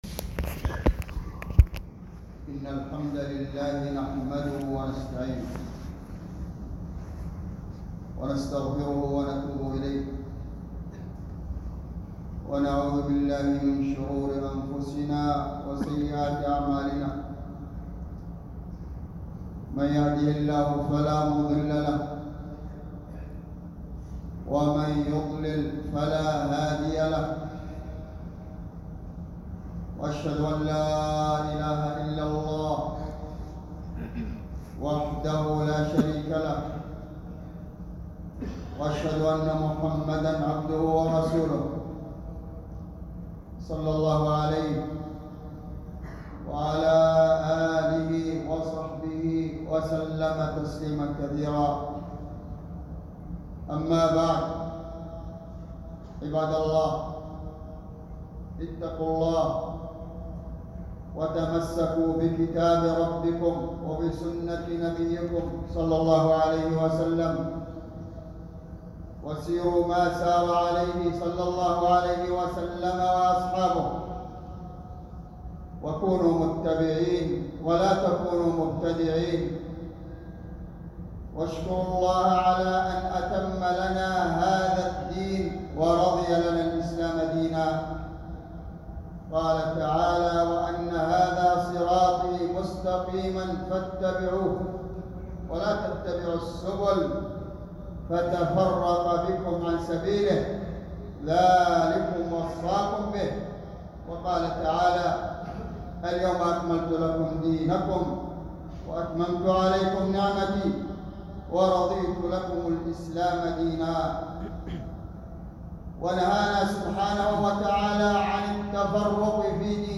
خطبة جمعة بعنوان: لا يصح في فضل رجب شيء